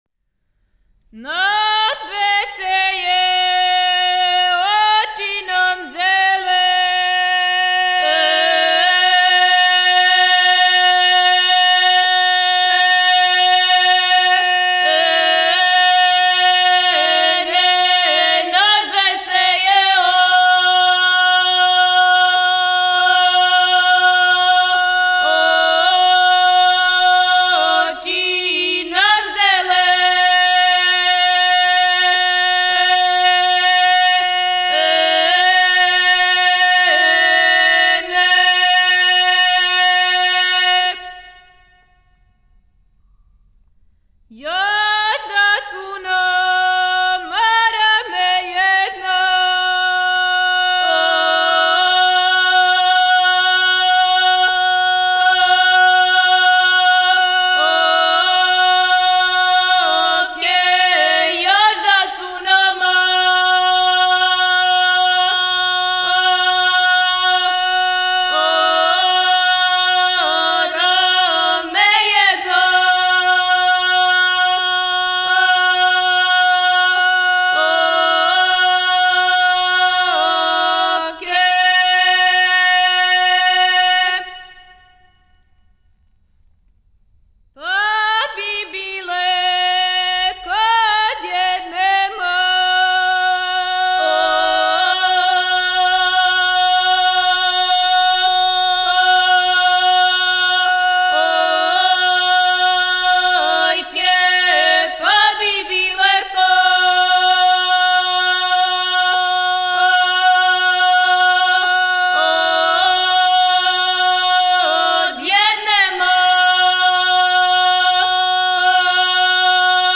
Снимци Етномузиколошки одсек Музичке школе "Мокрањац", Београд (4 MB, mp3) О извођачу Албум Уколико знате стихове ове песме, молимо Вас да нам их пошаљете . Порекло песме: Косјерић Начин певања: На глас.